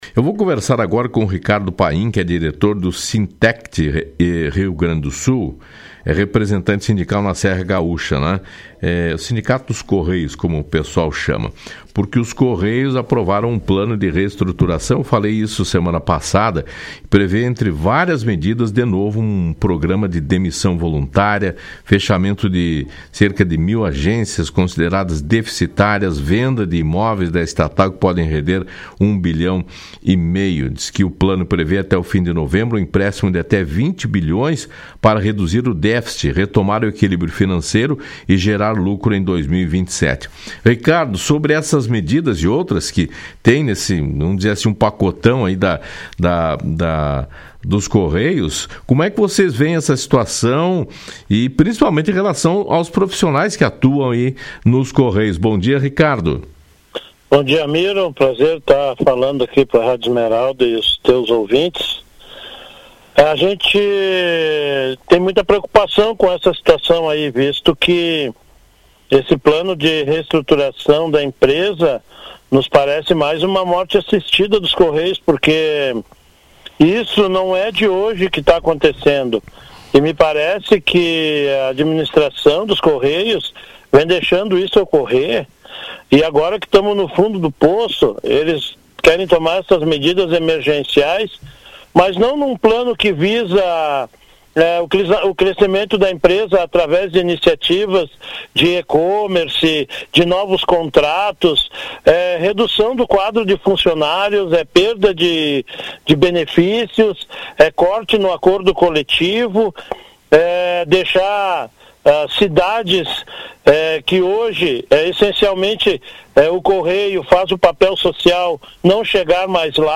FALA-CIDADE-ENTREVISTA-CORREIOS.mp3